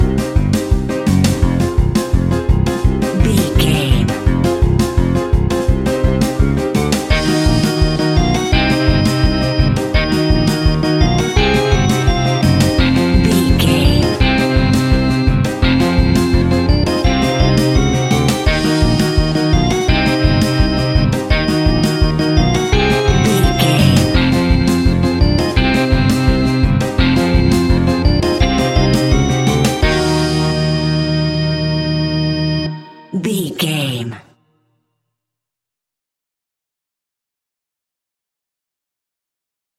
Atonal
tension
ominous
dark
eerie
horror music
horror instrumentals
Horror Pads
horror piano
Horror Synths